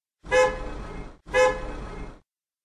bocina de auto cortito